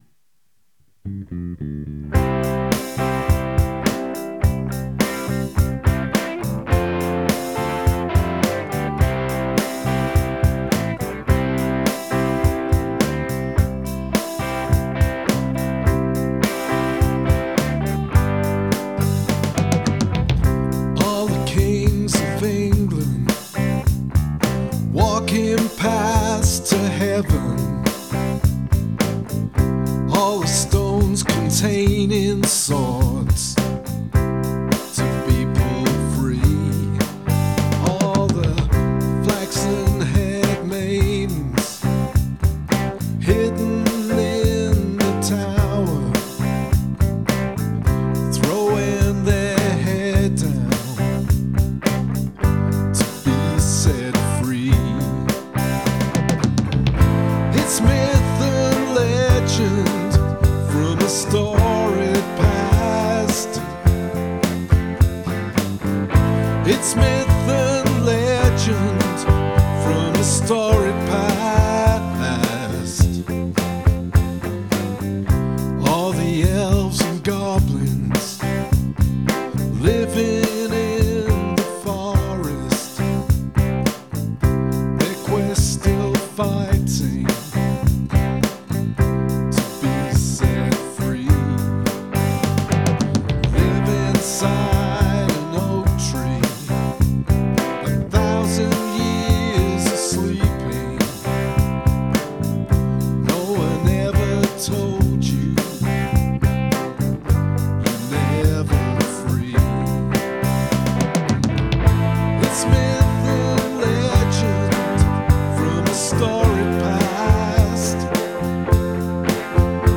yeah Neil Young indeed, good voice! love the instruments. Soulful vox. nice electric piano!
The production and the mix are both marvelous! Really like that guitar tone and the bass, which reflects what's going on lyrically very well!